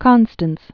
(kŏnstəns)